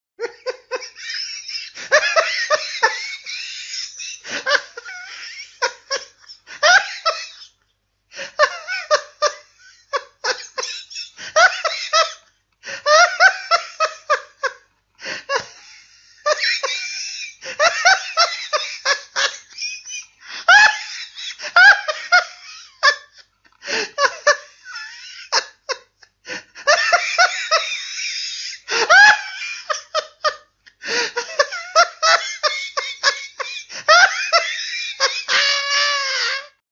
Sons - Effets Sonores